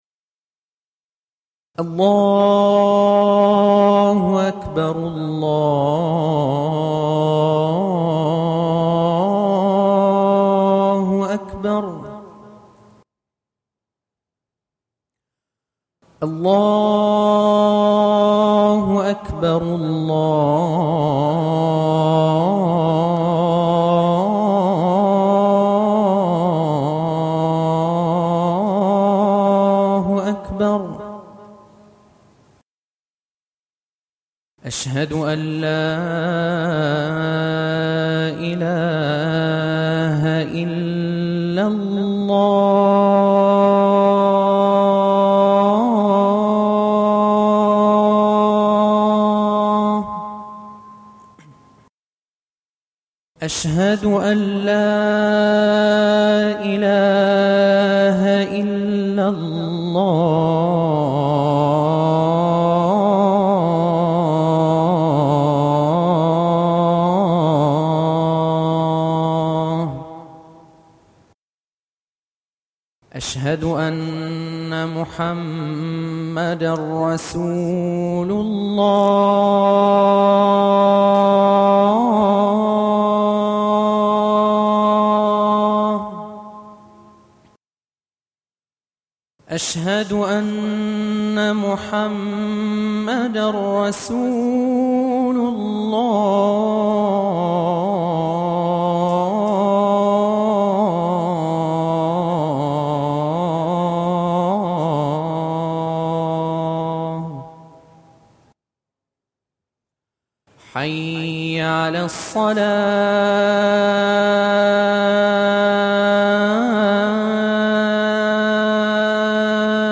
أذان